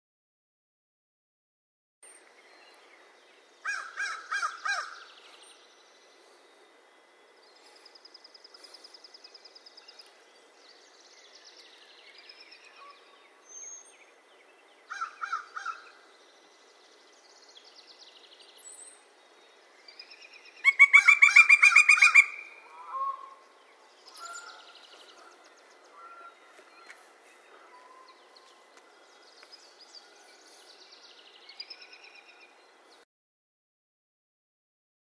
5. Pileated Woodpecker (Dryocopus pileatus)
Call: A wild, loud “cuk-cuk-cuk” and deep drumming.